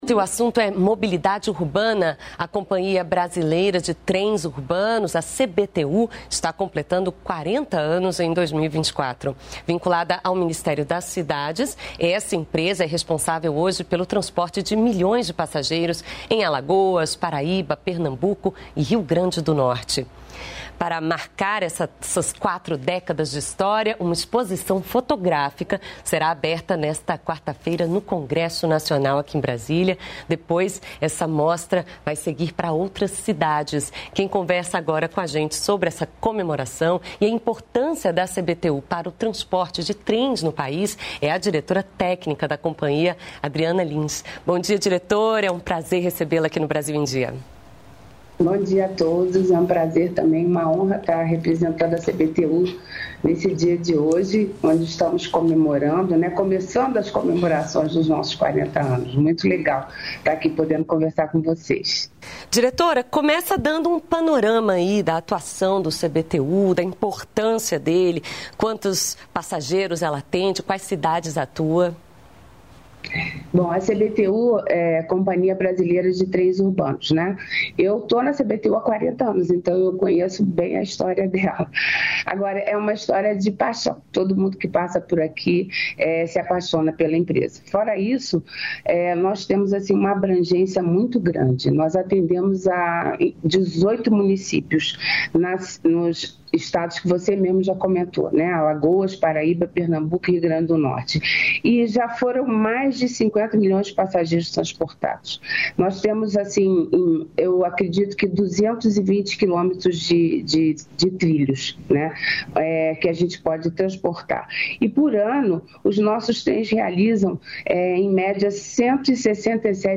Ricardo Galvão Presidente do CNPq-Conselho Nacional de Desenvolvimento Científico e Tecnológico fala do crescimento de 50% para edital de pesquisa é uma demonstração de que o governo voltou a valorizar o conhecimento científico.
Brasil em Dia - Entrevista